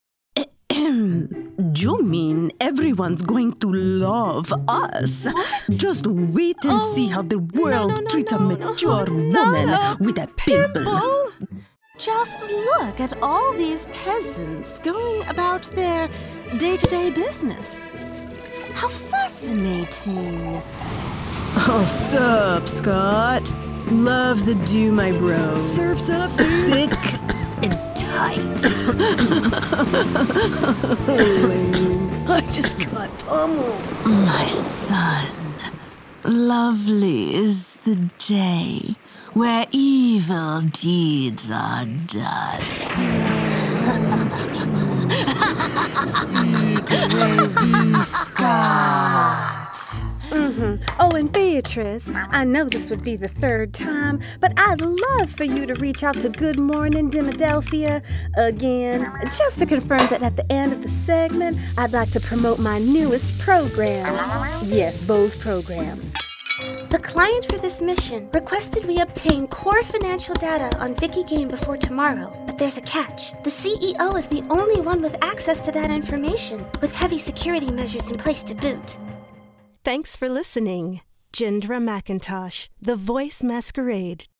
Animation Demo
FRENCH, RP, COCKNEY, US REGIONAL